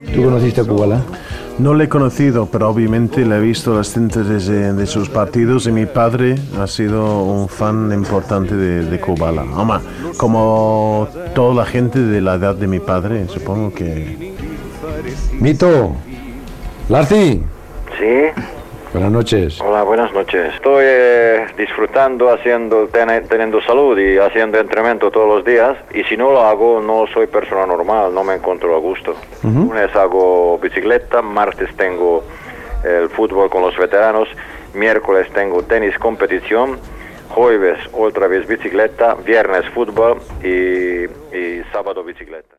Entrevista a l'exfutbolista i entrenador retirat Ladislao Kubala. Explica com es maté en forma als 66 anys
Esportiu